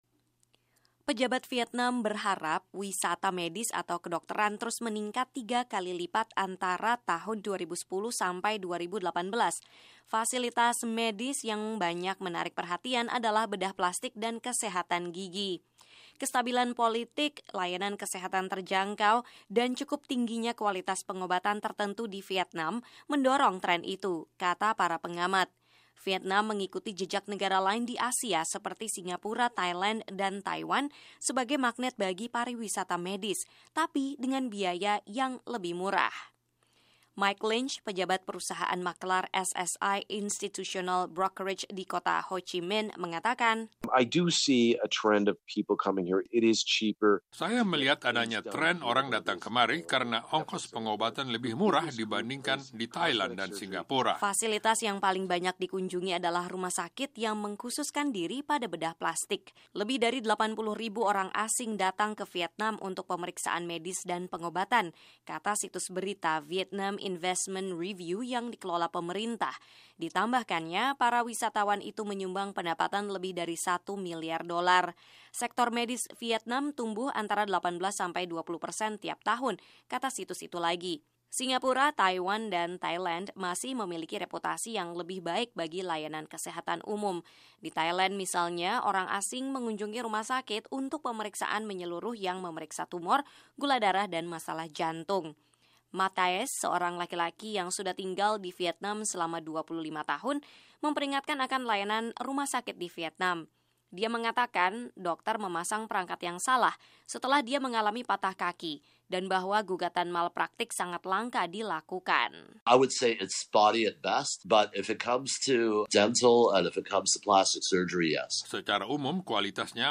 Tetapi kini banyak wisatawan datang berobat ke rumah sakit dan klinik Vietnam. Laporan VOA